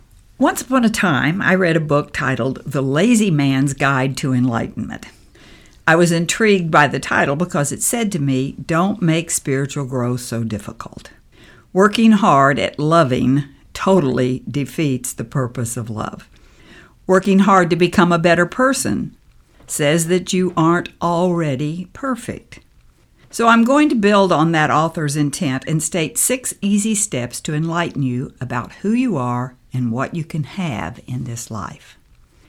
It is an audio guidebook to your inner journey – one that cycles through Gratitude, our daily chores, our realness and our illusions, only to end up with Forgiveness (inside and out).